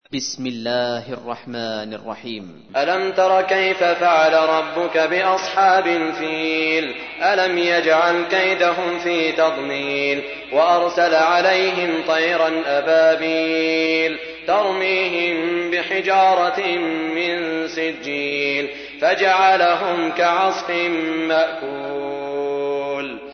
تحميل : 105. سورة الفيل / القارئ سعود الشريم / القرآن الكريم / موقع يا حسين